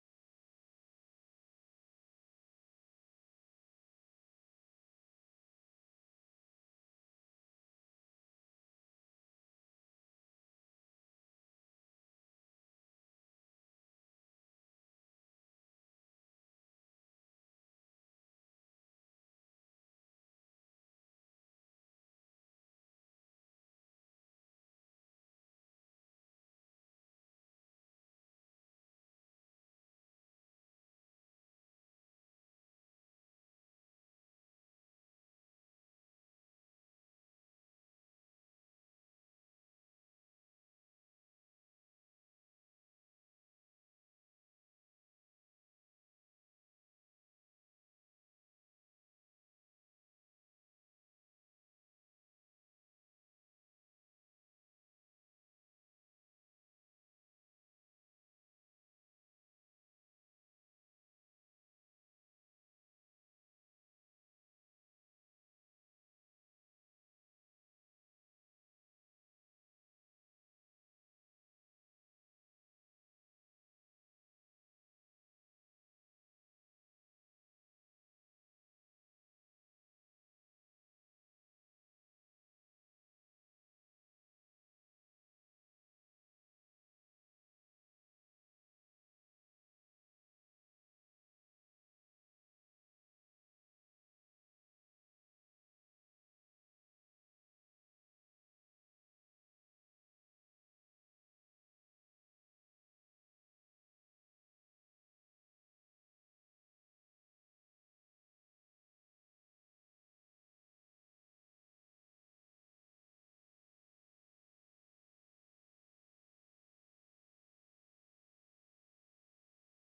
Timer Countdown 15 minTimer Countdown sound effects free download